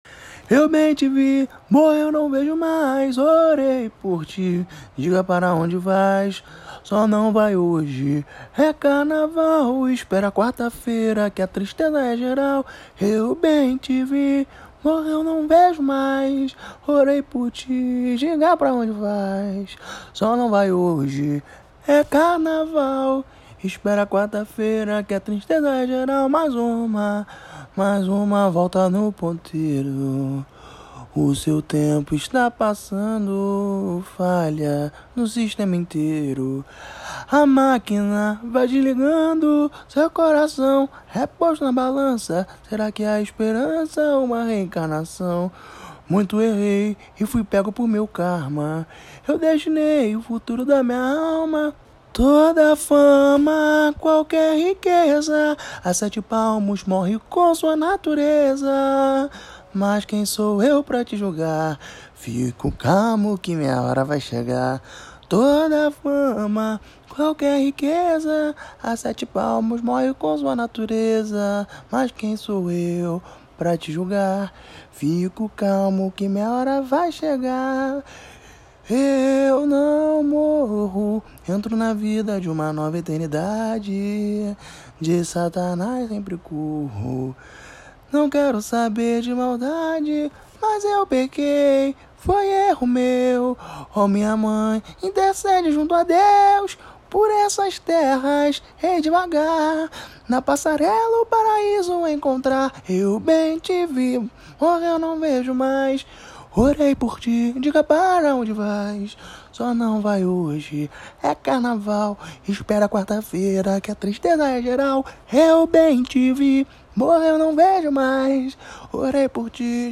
Samba  01